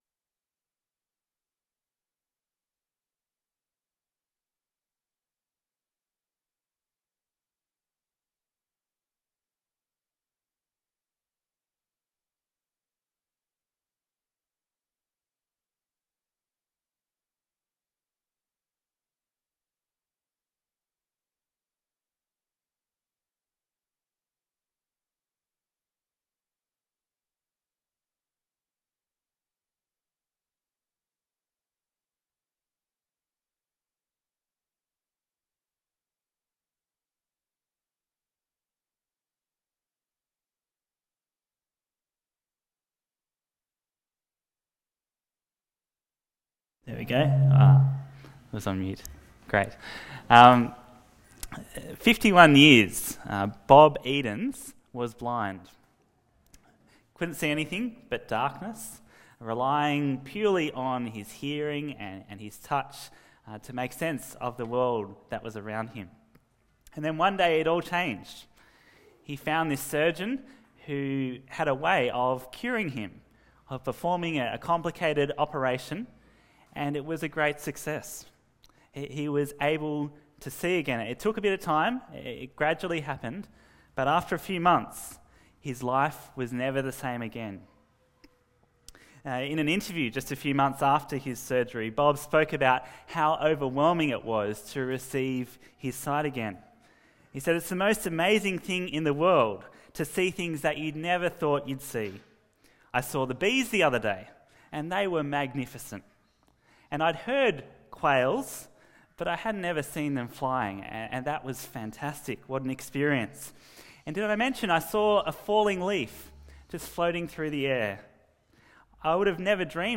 Bible Talks